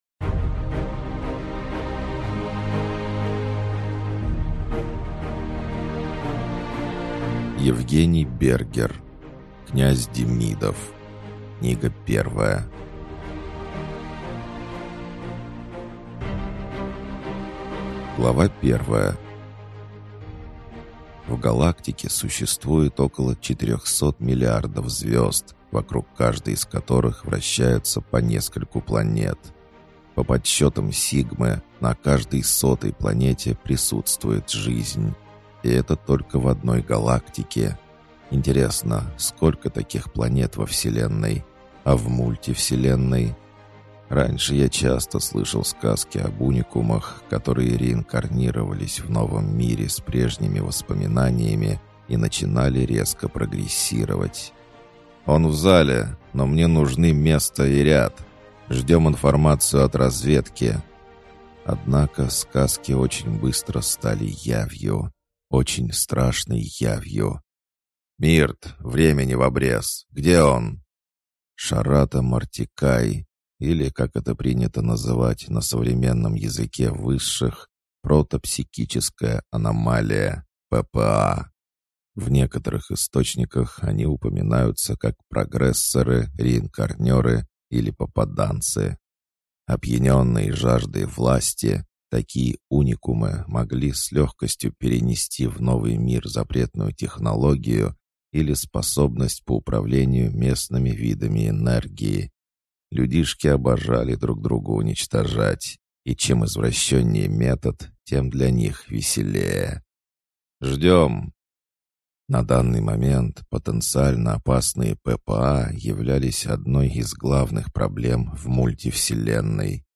Аудиокнига Князь Демидов. Книга 1 | Библиотека аудиокниг